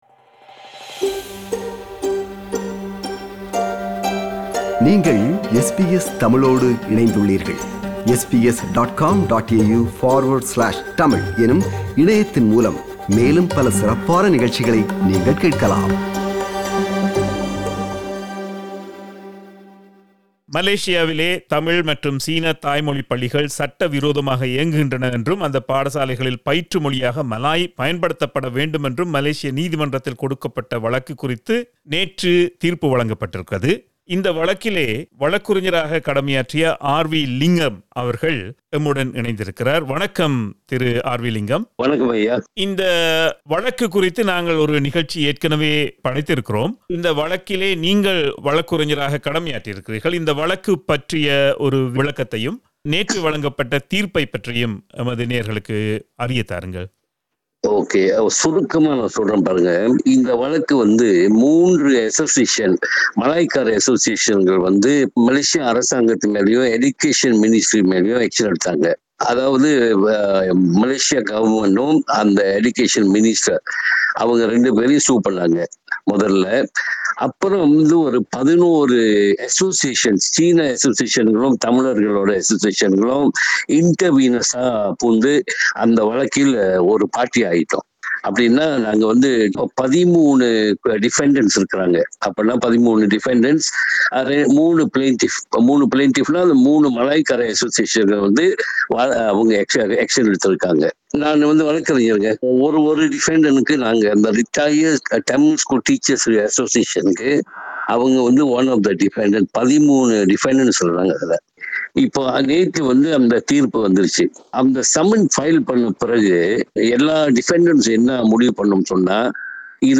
Listen to SBS Tamil at 8pm on Mondays, Wednesdays, Fridays and Sundays on SBS Radio 2.